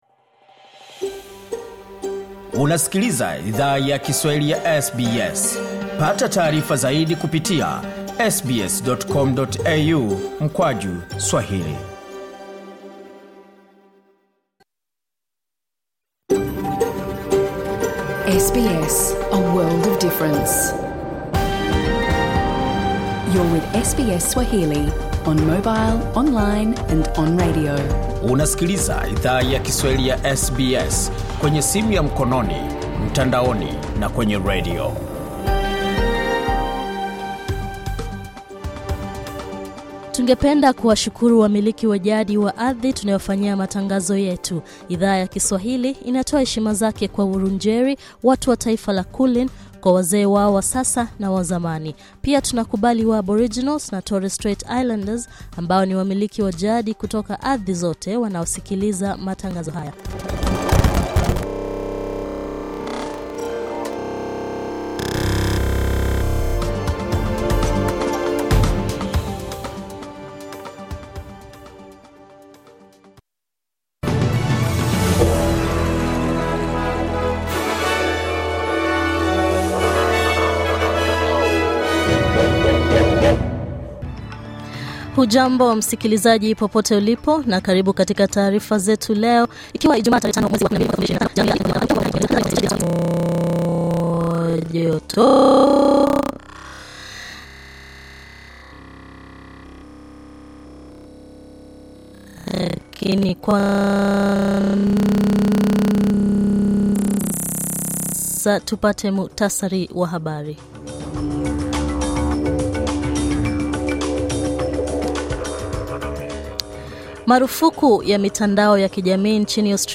Taarifa ya habari:Keir Starmer amlaumu Vladimir Putin kwa kifo cha mwanamke mwaka 2018